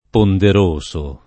ponderoso [ ponder 1S o ] agg.